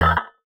UIClick_Metal Hits Muffled 02.wav